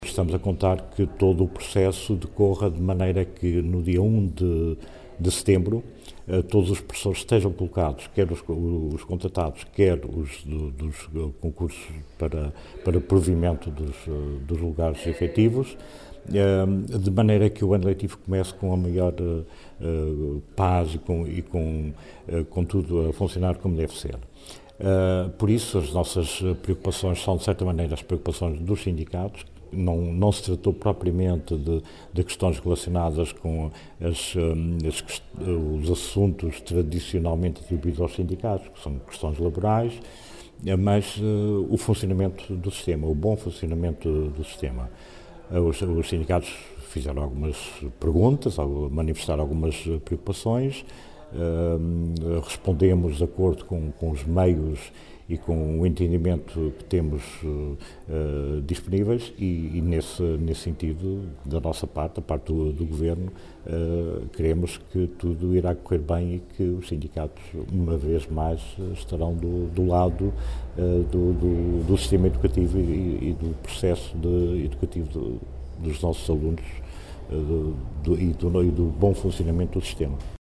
Luíz Fagundes Duarte, que falava em Angra do Heroísmo, no final de uma ronda de reuniões com os dois sindicatos representativos dos professores dos Açores, salientou que, na sequência dos concursos extraordinários para docentes, “está tudo a ser feito para cumprir rigorosamente com aquilo que foi nesta matéria determinado pela Assembleia Legislativa”.